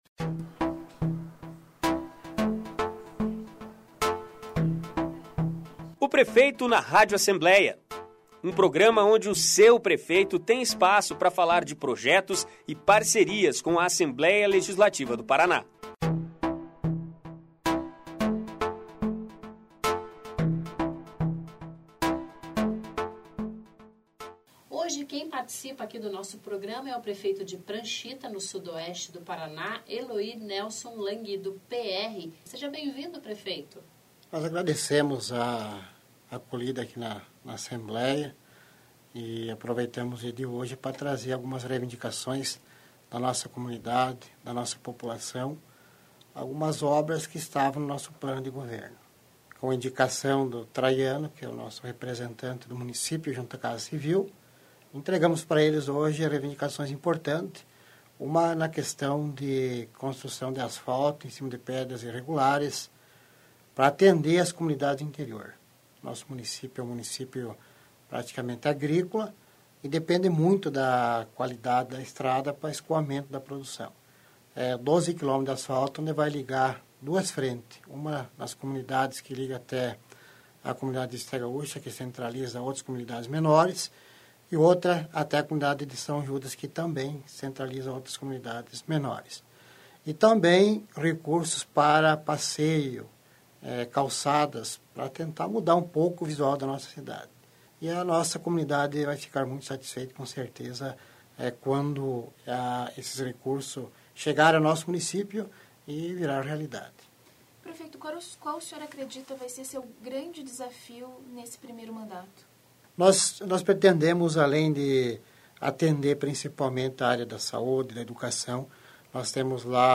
Ouça a aentreviata com o prefeito de Pranchita, Eloir Nélson Lang (PR). Ele é o convidado desta semana do programa de rádio  "Prefeito na Rádio Alep".